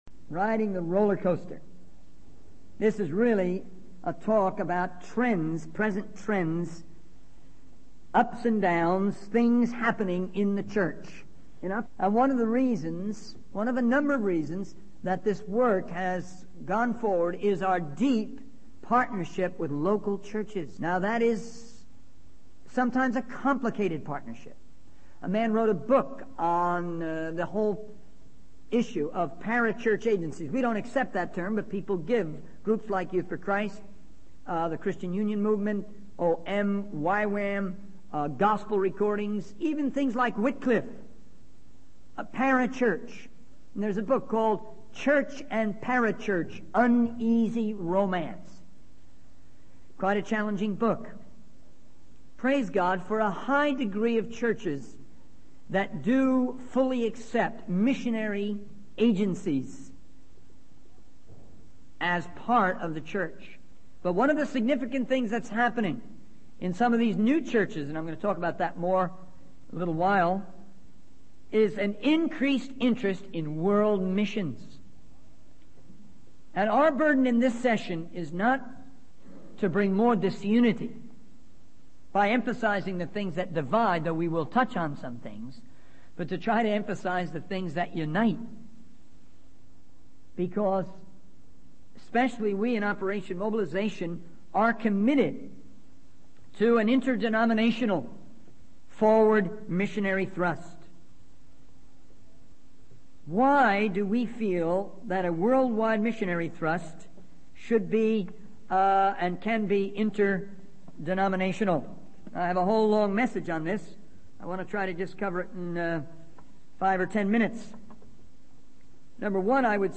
In this sermon, the speaker shares ten points of wisdom for living a life of faith and service. These points include the importance of loving and helping others, even when they may not appreciate it or attack you. The speaker also emphasizes the need to stay focused on sound doctrine and biblical principles, rather than getting caught up in exceptions or new trends.